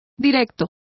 Complete with pronunciation of the translation of forthright.